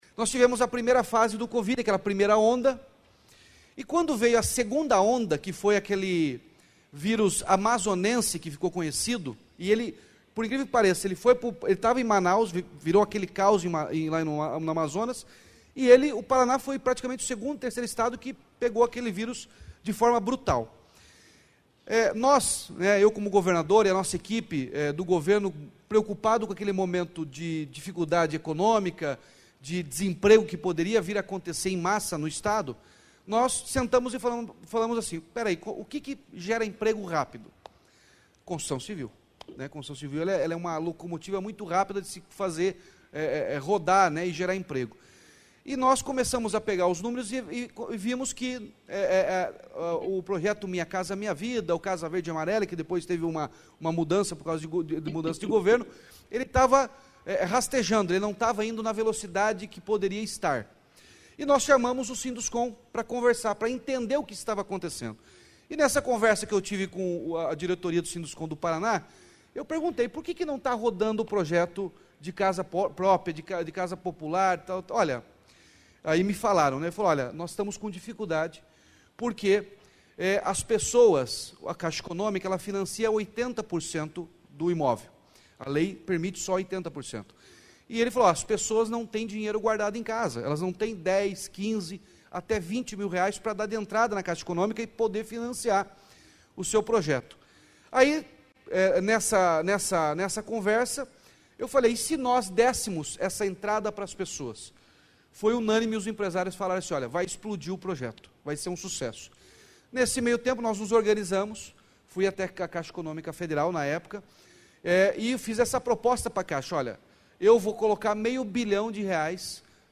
Sonora do governador Ratinho Junior sobre o programa Casa Fácil Paraná no Encontro Nacional da Indústria da Construção Civil, em São Paulo